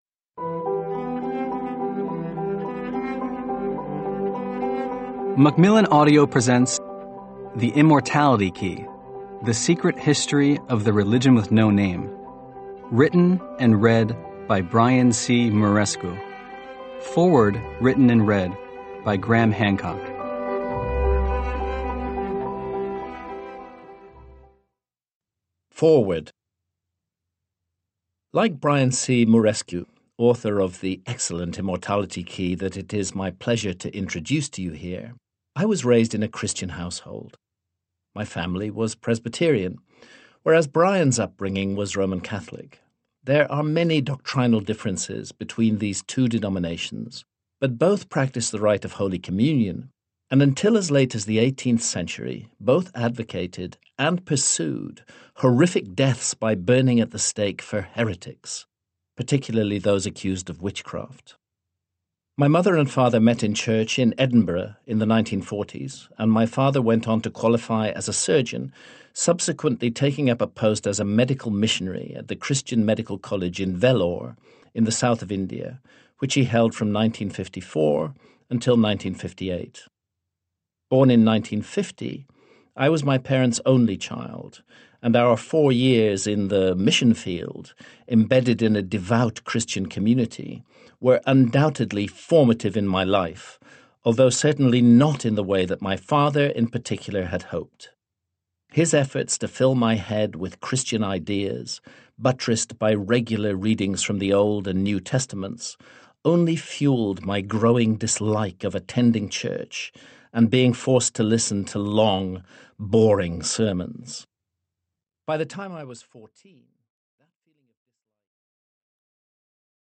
digital digital digital stereo audio file Notes: Electronic audio file